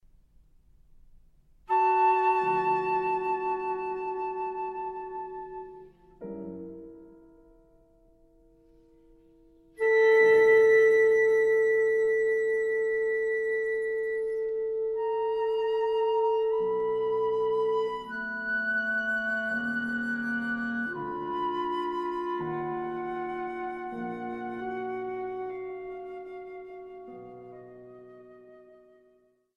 Flute
Clarinet
Piano.